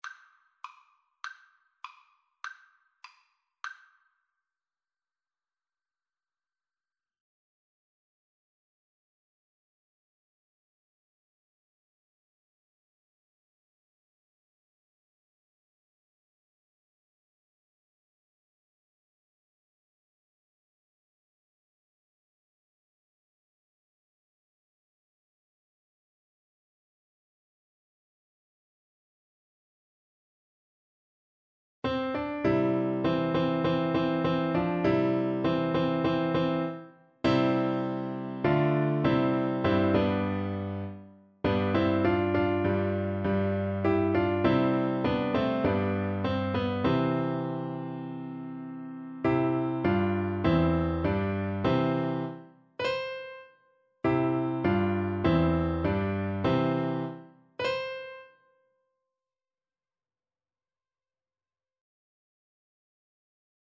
2/4 (View more 2/4 Music)
Joyfully
world (View more world Voice Music)